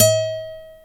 Index of /90_sSampleCDs/Roland L-CD701/GTR_Nylon String/GTR_Nylon Chorus
GTR NYLON20F.wav